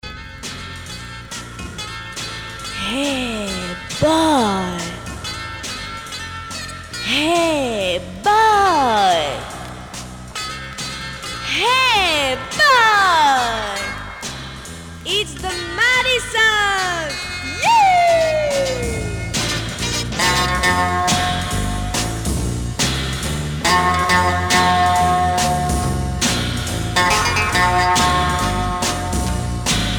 Rock instrumental Premier EP retour à l'accueil